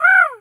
pgs/Assets/Audio/Animal_Impersonations/crow_raven_call_squawk_01.wav
crow_raven_call_squawk_01.wav